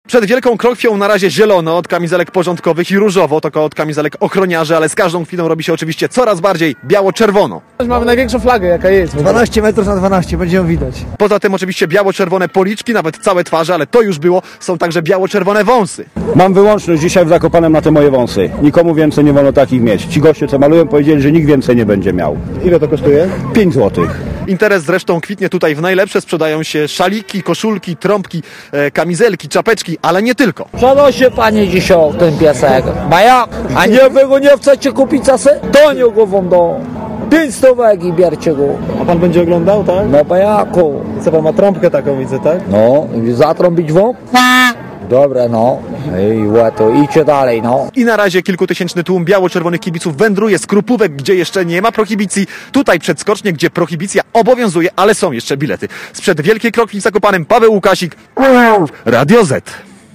© Polscy kibice śledzą skok Adama Małysza w kwalifikacjach na wielkiej krokwi (RadioZet)